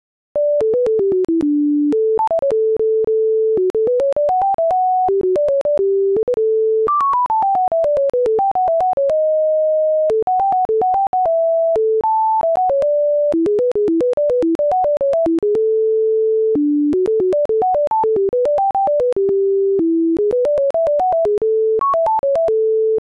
Each measure is written in 3/4, or waltz, time and the result is a 16-measure minuet.
To us, the melody is not without its pleasing aspects. For instance, the tune starts building something interesting in measures 11 through 13, but then doesn’t follow through and the ending is rather abrupt.
The above example of a game-produced tune sounds un-humanlike, but that doesn’t mean it isn’t possible to construct a system of music creation that produces more natural sounding melodies.